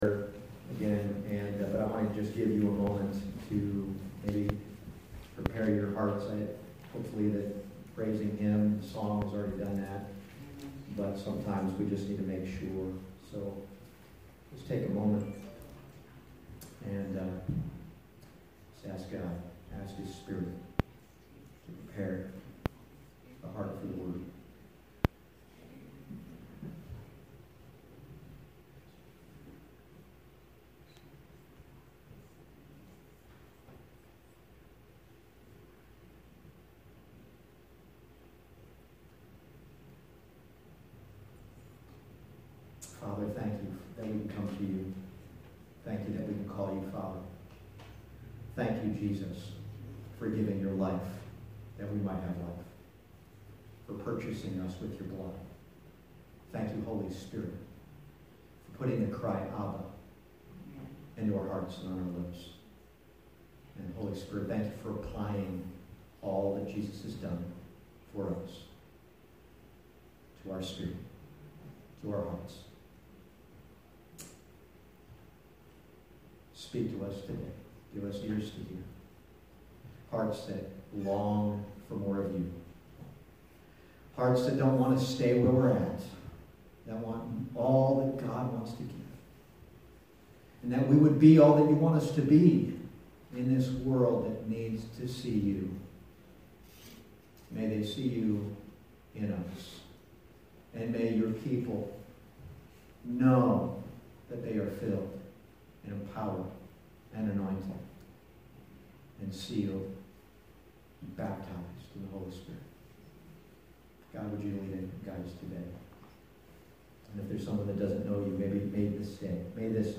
Passage: Ephesians 1:13-14 Service Type: Sunday Morning